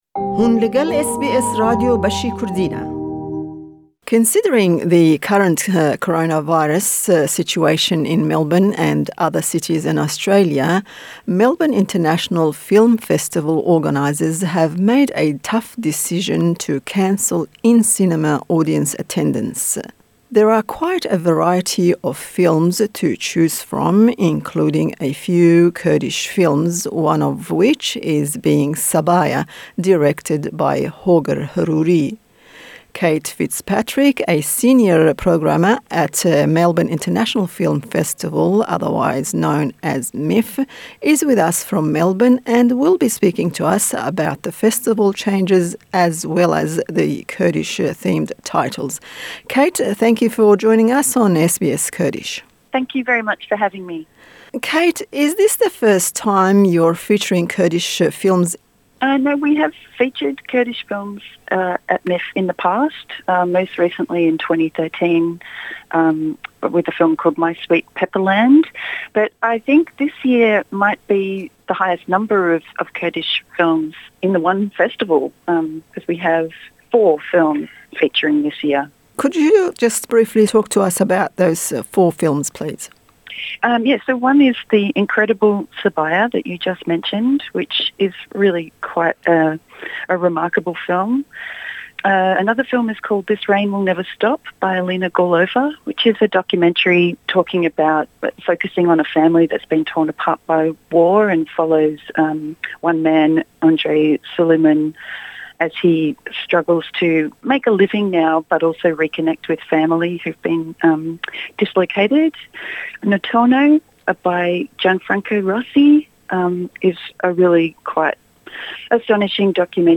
is with us from Melbourne and she will be talking to us about changes in the Festival as well as the Kurdish themed titles.